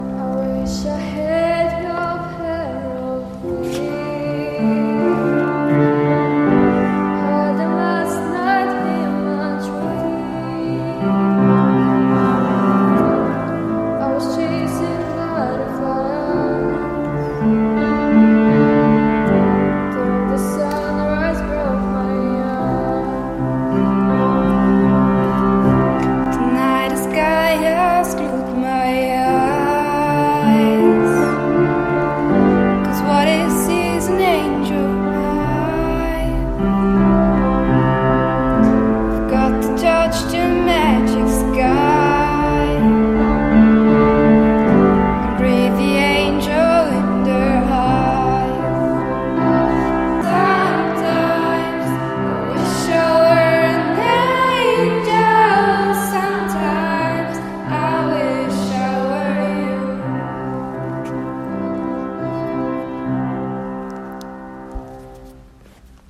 Freifach Chorsingen - Singen ist Trend
Unser Chor singt seit einem Jahr an verschiedenen Anlässen.